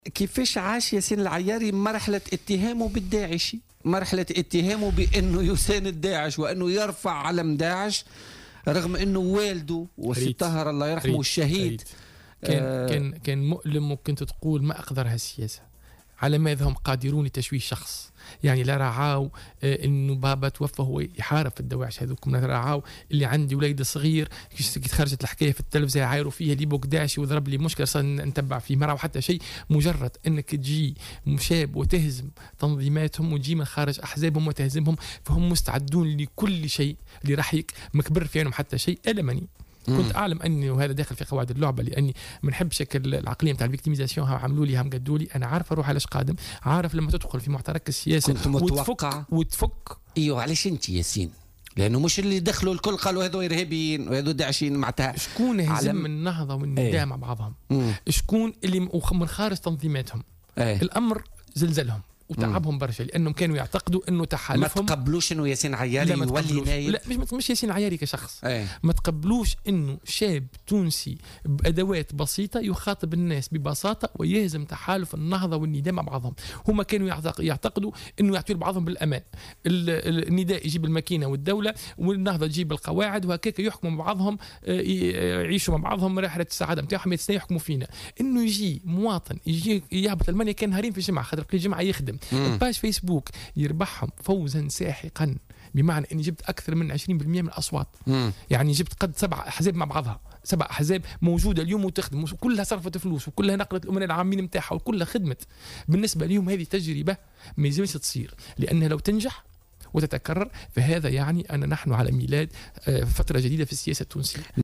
وتابع العياري، ضيف برنامج "بوليتيكا" اليوم : ما أقذر السياسة..بمجرد أن تهزمهم وأنت من خارج أحزابهم، فهم مستعدون لسحقك".